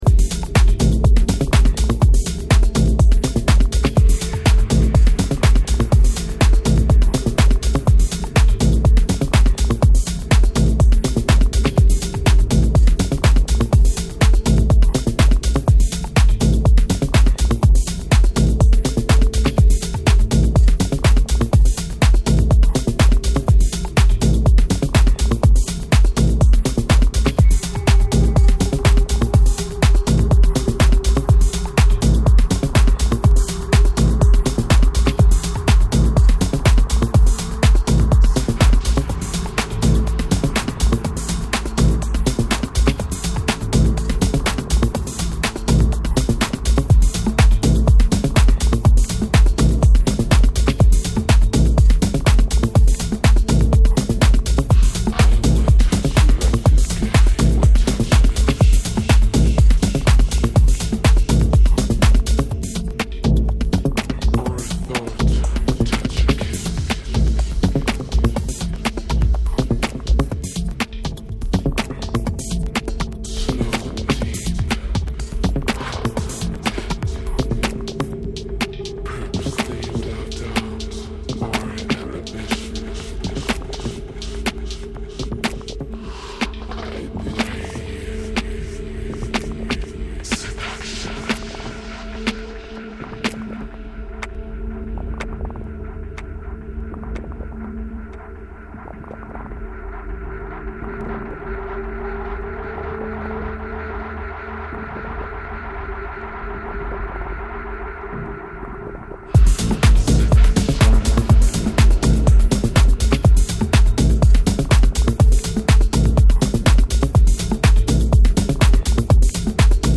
minimal and house cuts